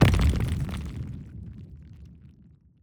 Wall Break.wav